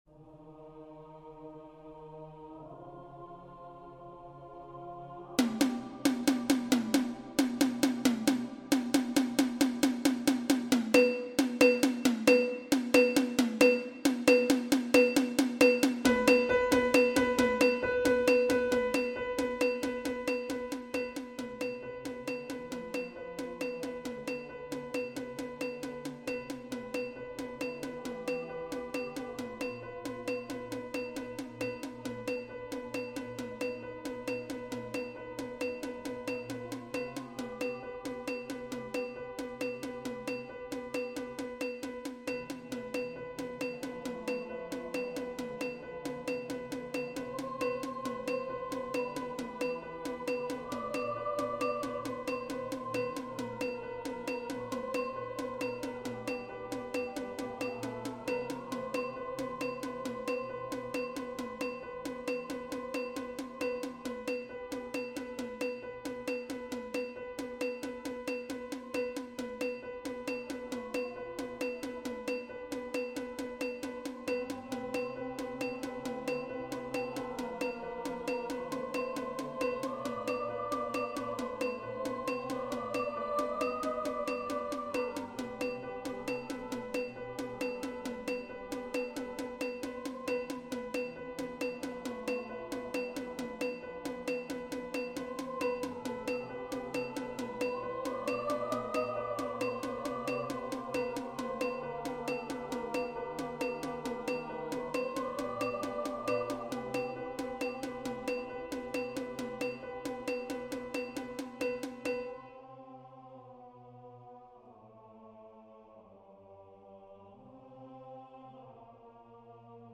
Unknown Number of voices: 4vv Voicing: SATB Genre: Secular, Anthem
Language: English Instruments: Percussion
First published: 2025 Description: Traditional text for SATB and three easy percussion parts.